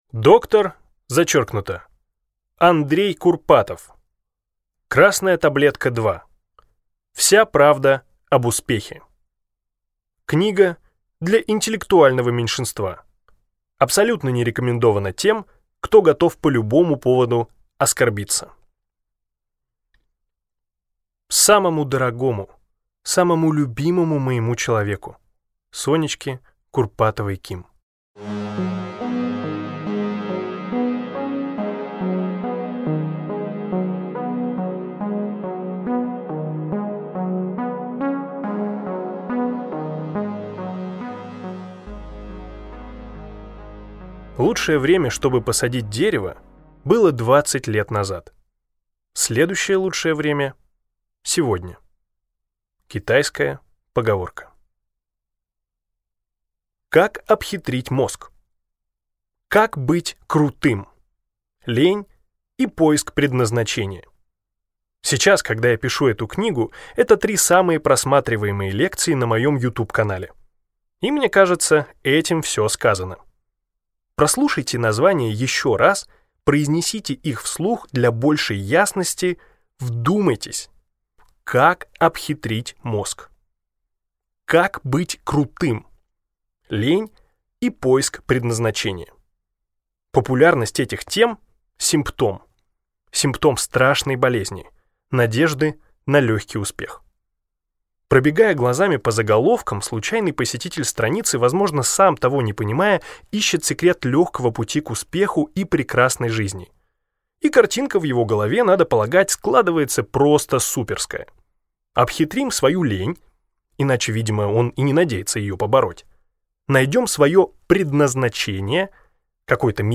Аудиокнига Красная таблетка 2
Качество озвучивания весьма высокое.